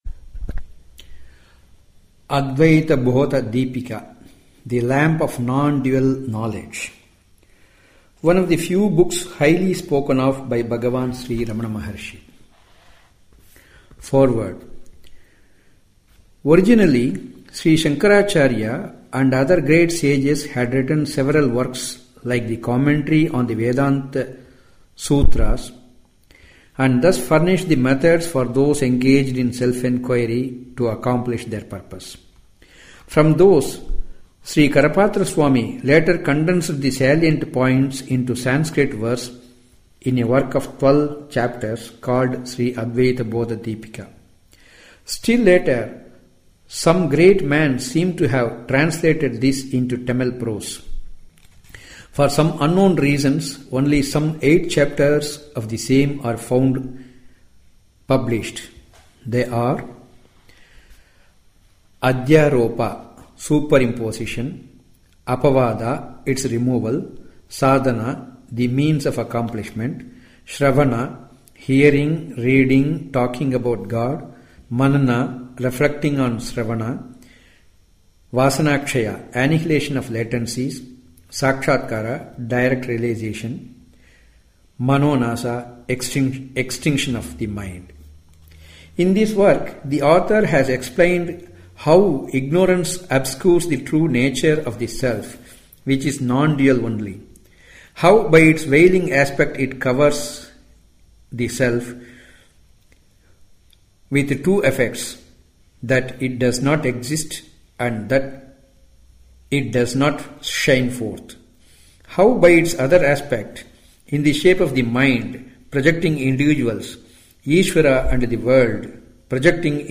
narration of the Introduction published by Sri Ramanasramam